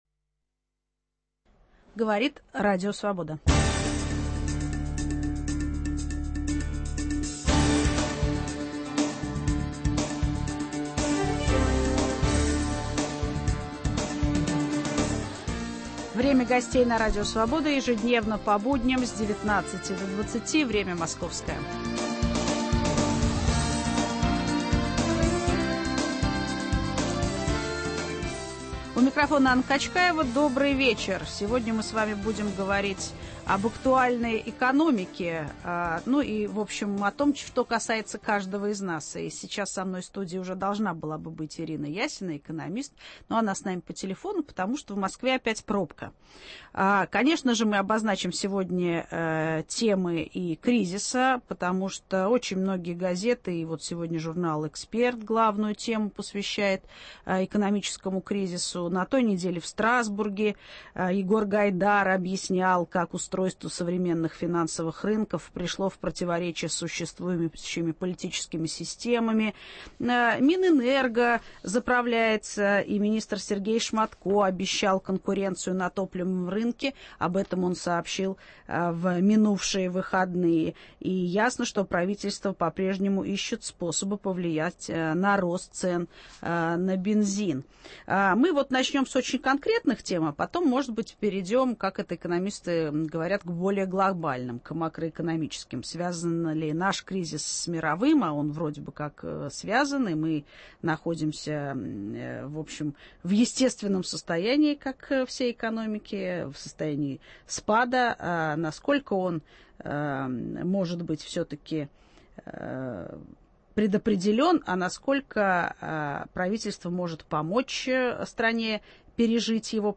Об актуальной экономической ситуации разговариваем с экономистом Ириной Ясиной.